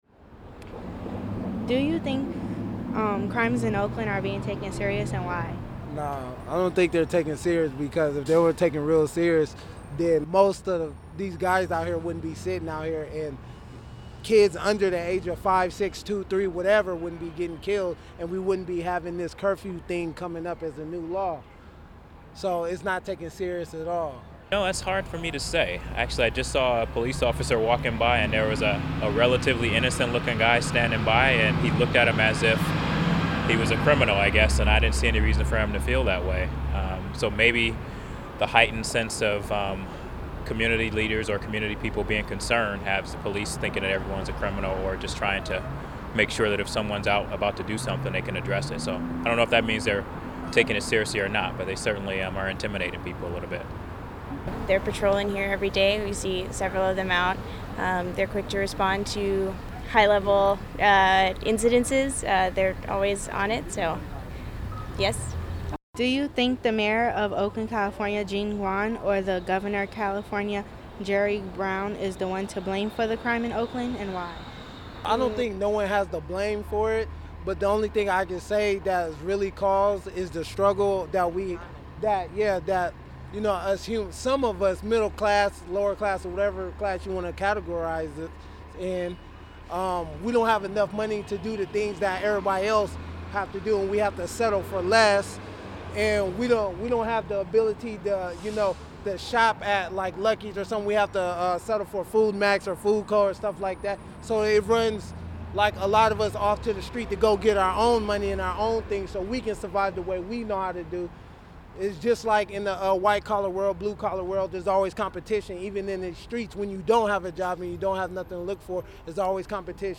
I walked down the streets of downtown Oakland and interviewed a few people on their opinion about crime in Oakland.
Tags: crime , downtown Oakland , Interview , Law Enforcement , Oakland police